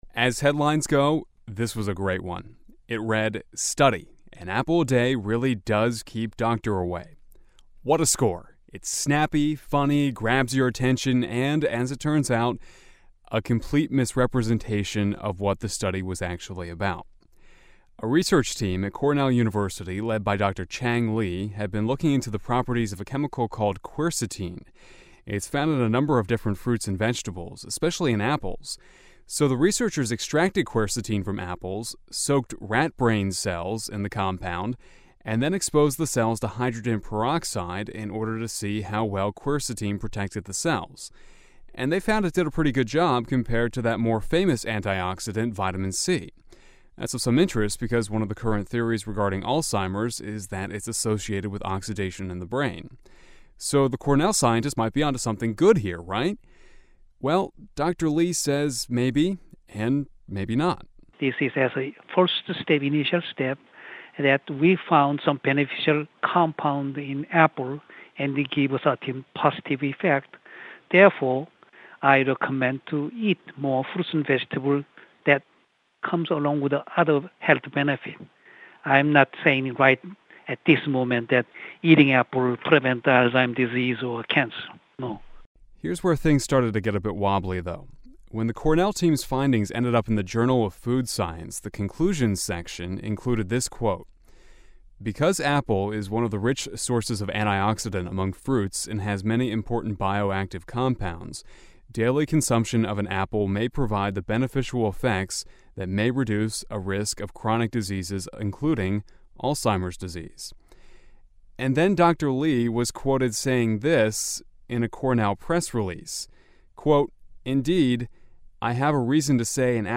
(originally aired February 03, 2005)